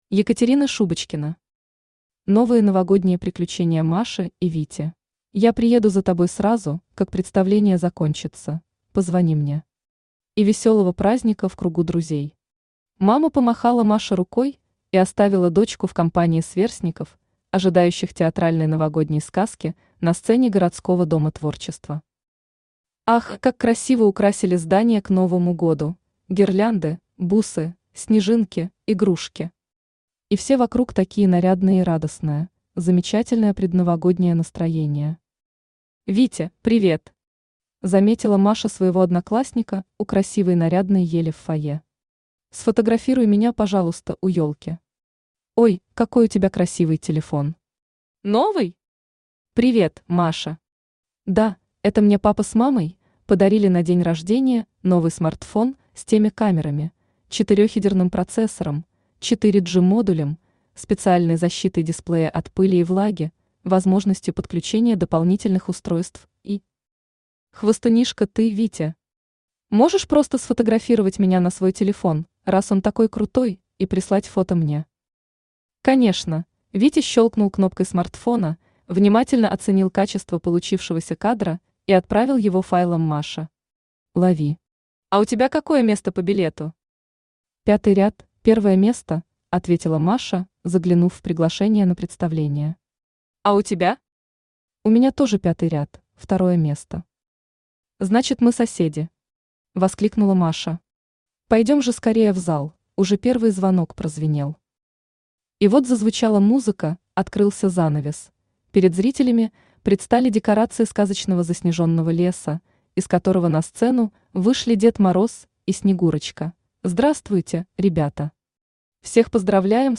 Аудиокнига Новые новогодние приключения Маши и Вити | Библиотека аудиокниг
Aудиокнига Новые новогодние приключения Маши и Вити Автор Екатерина Шубочкина Читает аудиокнигу Авточтец ЛитРес.